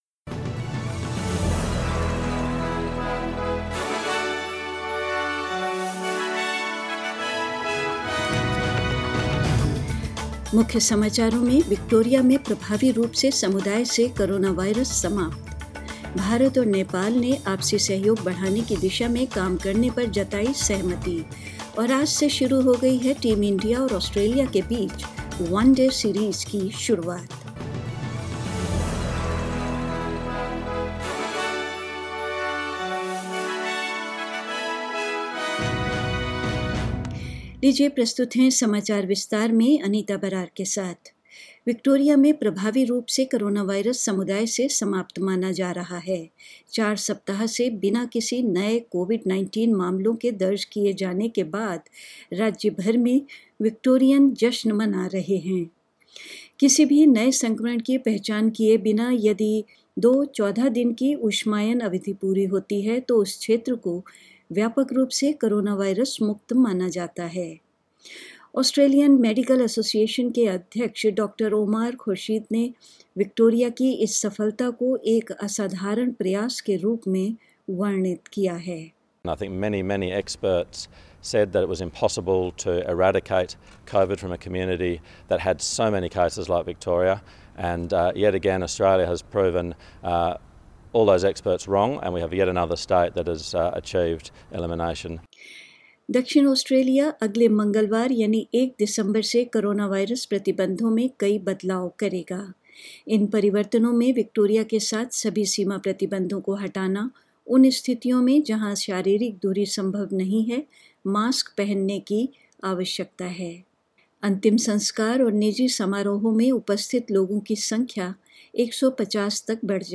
News in Hindi 27th November 2020